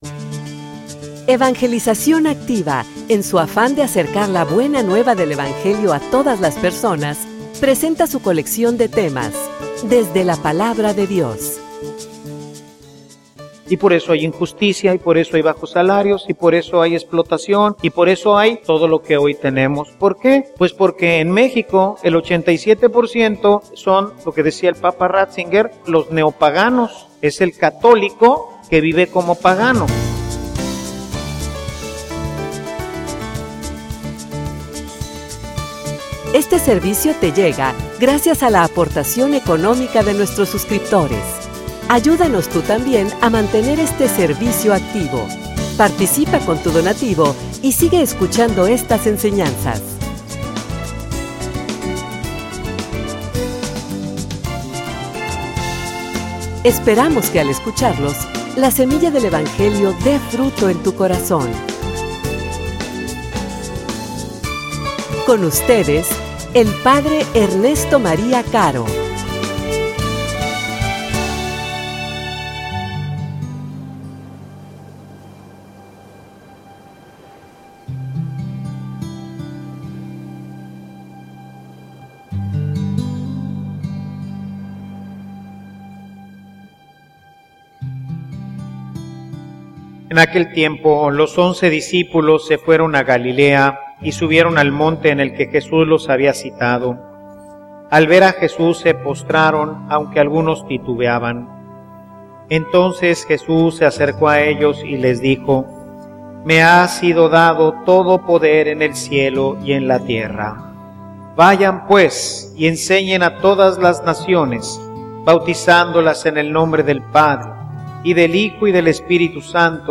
homilia_Manos_a_la_obra.mp3